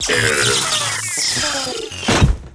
droid.wav